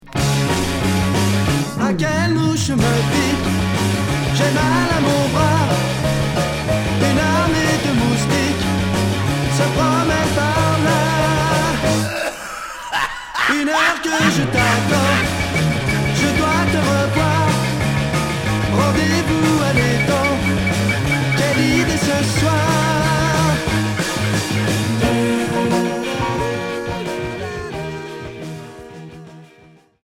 Heavy Pop Premier 45t retour à l'accueil